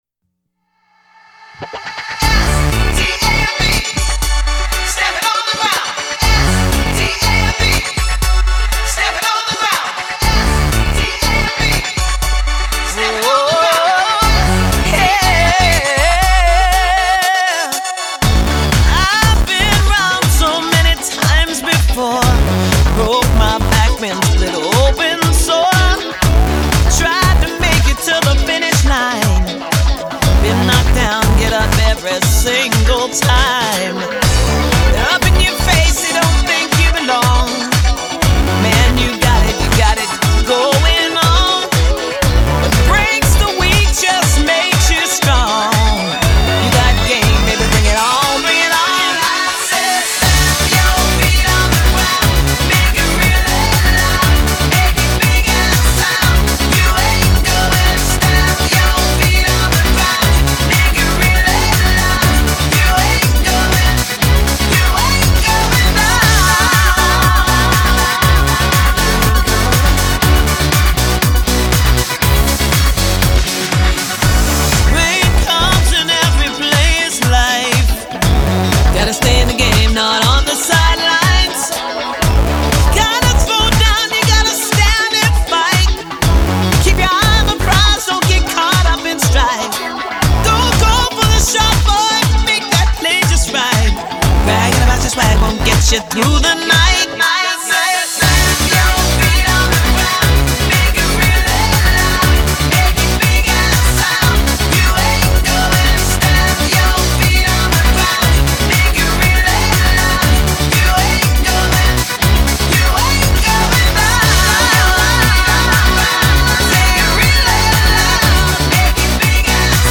Pop, Dance, R&B, Funk